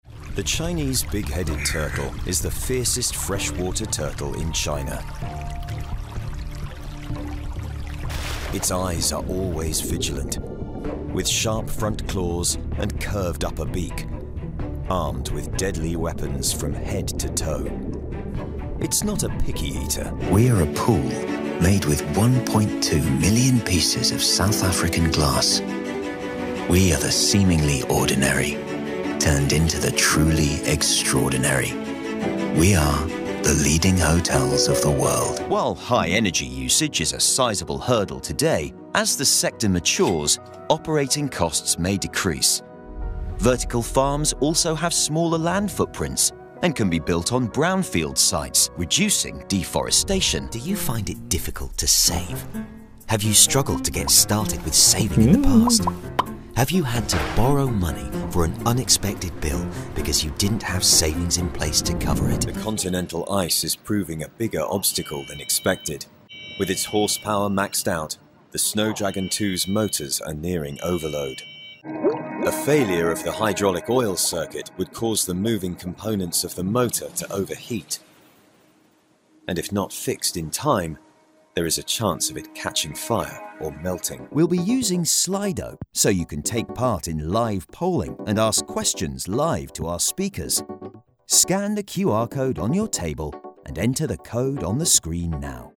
NARRATIVE/E-LEARNING/CORPORATE SHOWREEL
His deep, authentic RP voice lends youthful gravitas to commercials and narration, while his versatile accent range makes him a standout character actor for games, animation, and radio.
Male